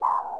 bip.wav